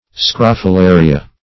Scrophularia \Scroph`u*la"ri*a\, n. [NL. So called because it